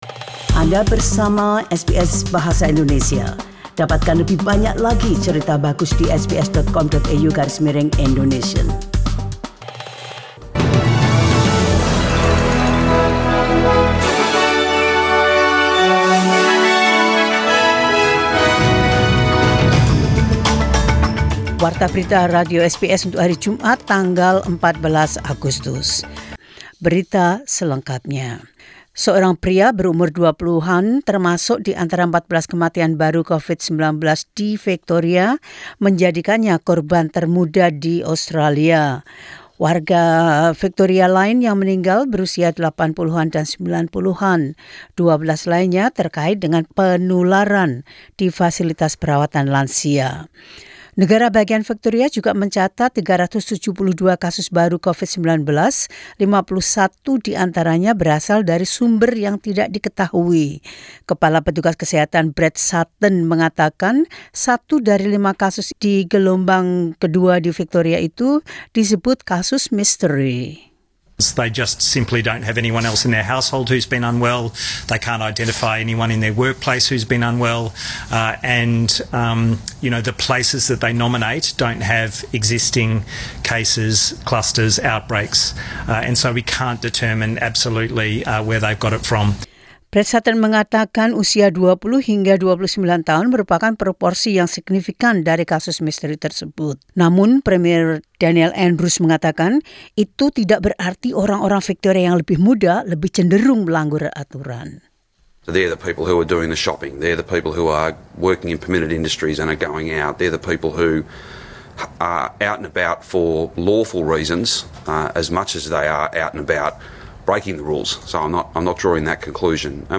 SBS News Indonesian Program – 14 Aug 2020.